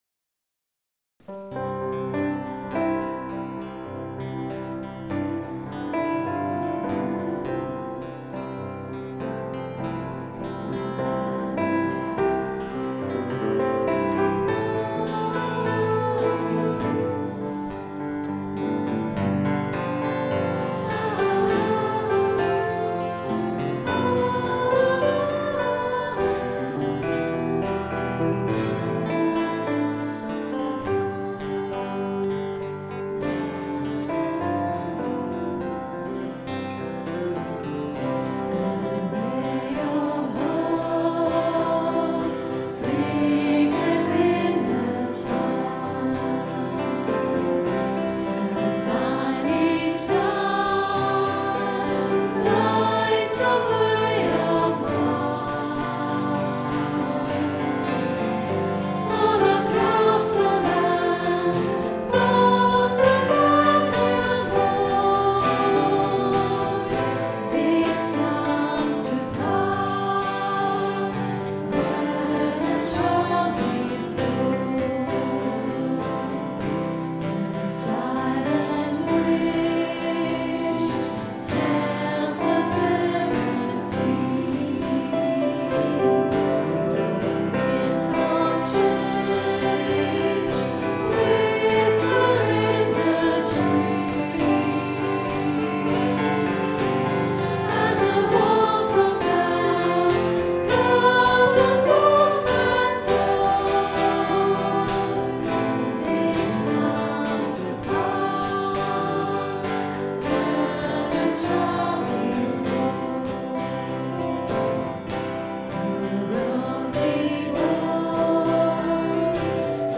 Recorded on a Zoom H4 digital stereo recorder at 10am Christmas Day Mass, 2010.